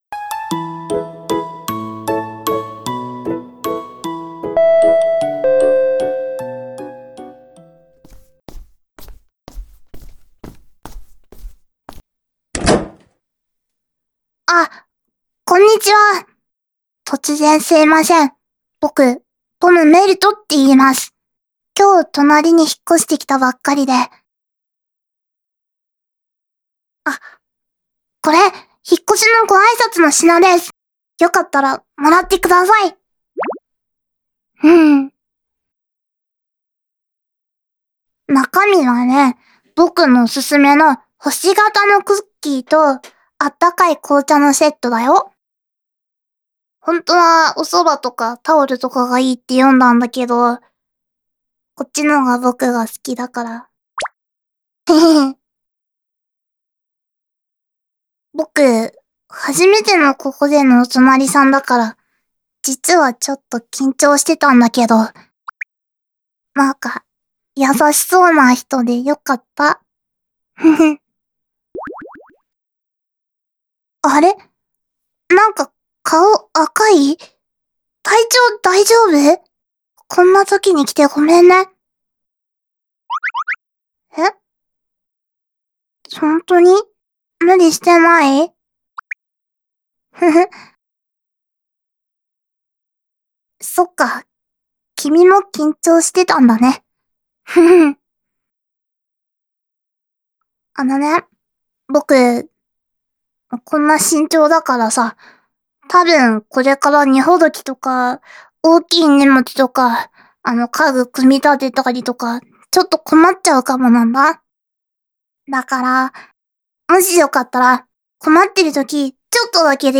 お引越しボイス
完全個人勢ショタボVtuber(〃>ω<〃)！